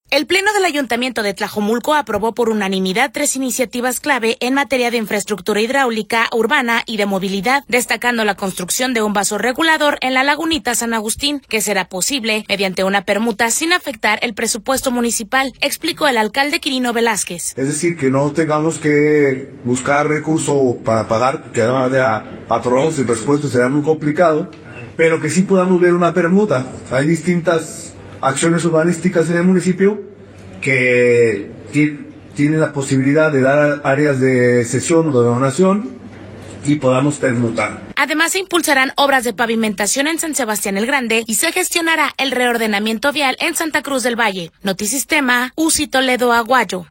El Pleno del Ayuntamiento de Tlajomulco aprobó por unanimidad tres iniciativas clave en materia de infraestructura hidráulica, urbana y de movilidad, destacando la construcción de un vaso regulador en La Lagunita, San Agustín, que será posible mediante una permuta sin afectar el presupuesto municipal, explicó el alcalde Quirino Velázquez.